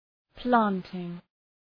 Προφορά
{‘plæntıŋ}